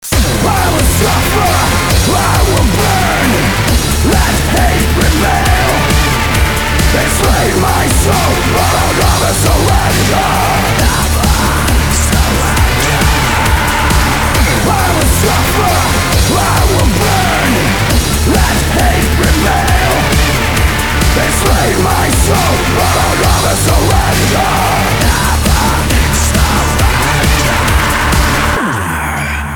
• Качество: 195, Stereo
громкие
жесткие
EBM
Industrial metal
Aggrotech